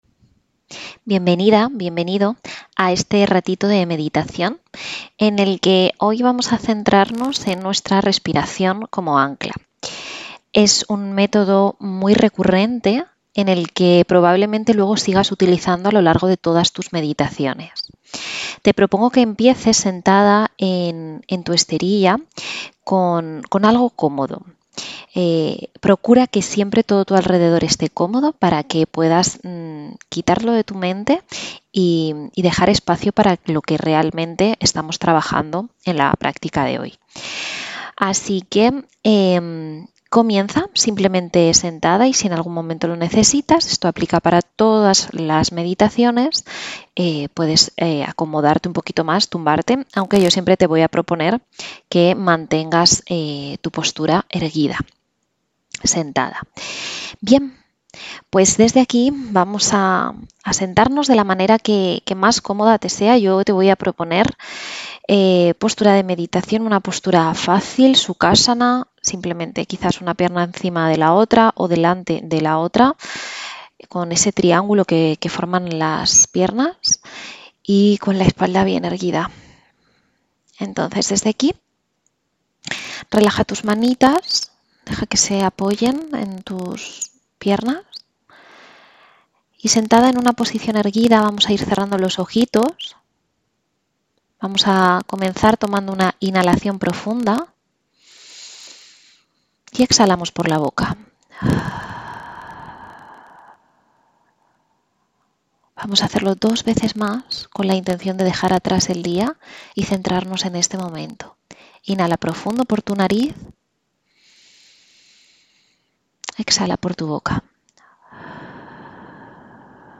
Med-2.-Repiracion-como-ancla-en-la-meditacion.mp3